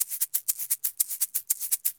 Eggs_ ST 120_2.wav